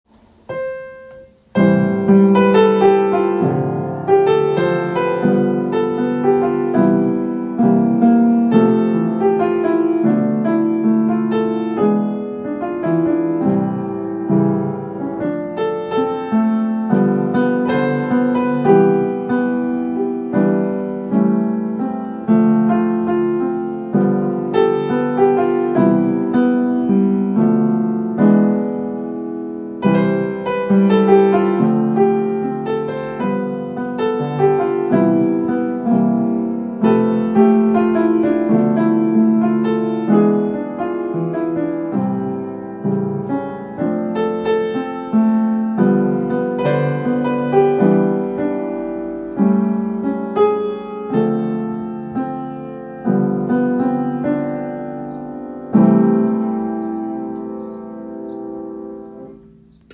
Piano d'étude SCHIMMEL